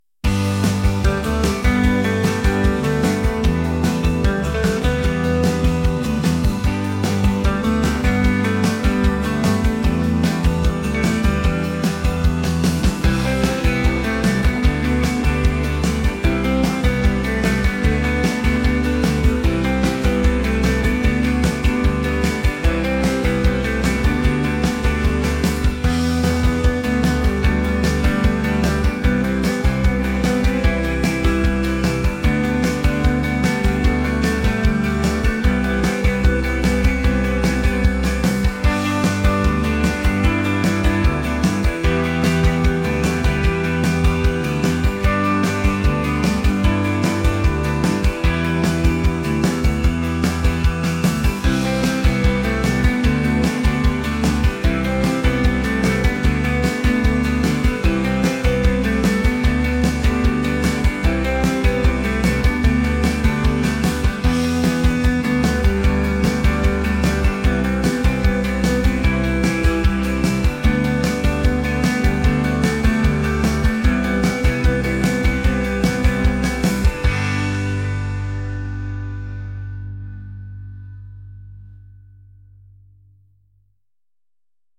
pop | upbeat | indie